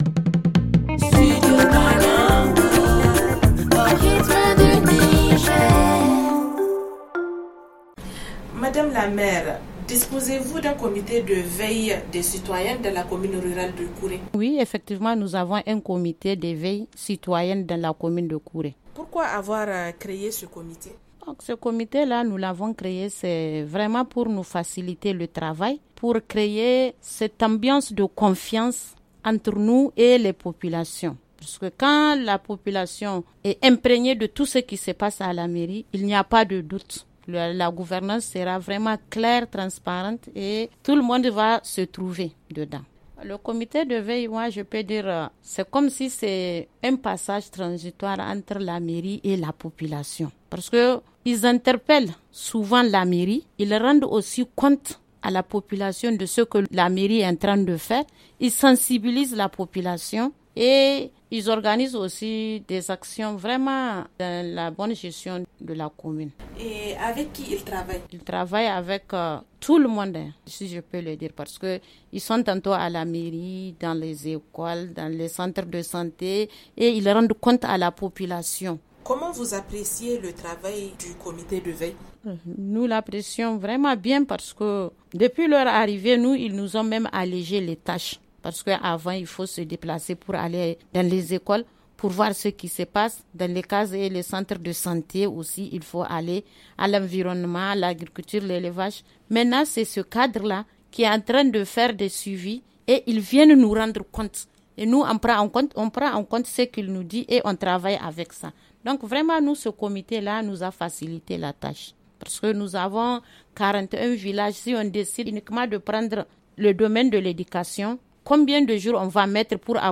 Madame Boureima Kadidja Seini, maire de Kouré explique comment il fonctionne ?